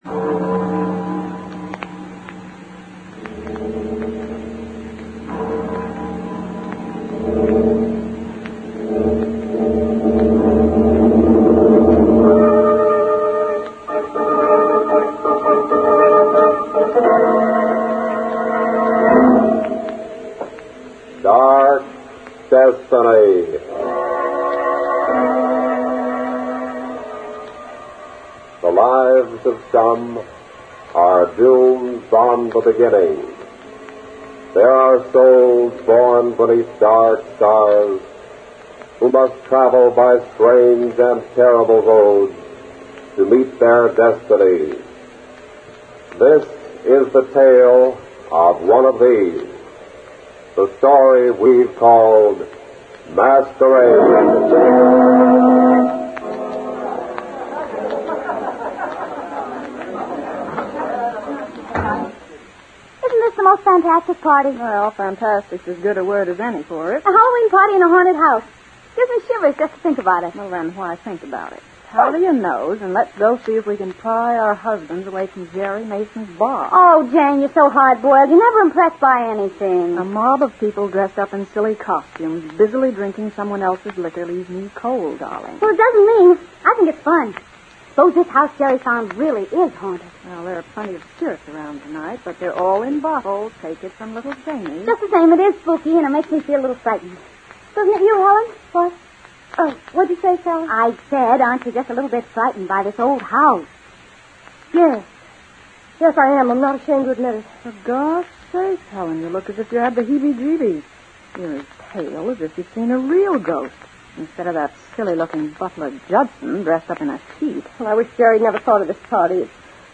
Only one recorded episode of Dark Destiny survives in circulation today. Although rather rare, the sound quality and script are both quite good.